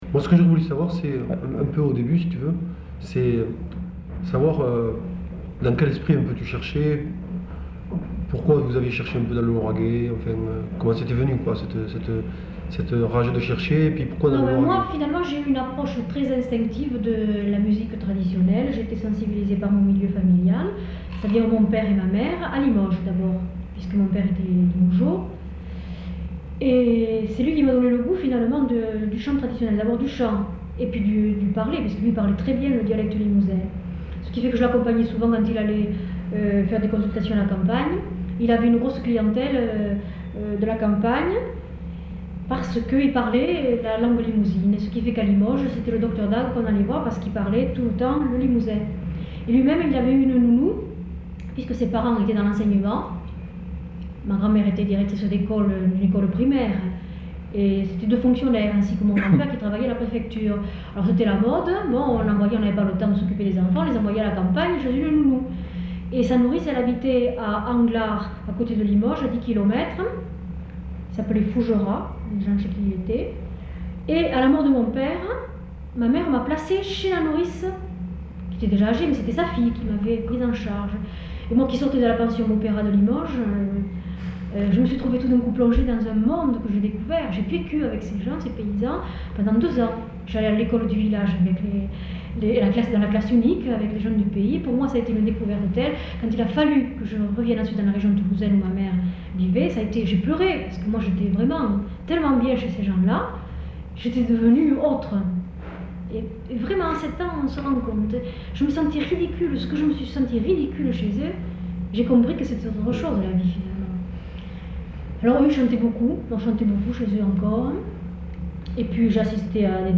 Lieu : Toulouse
Genre : récit de vie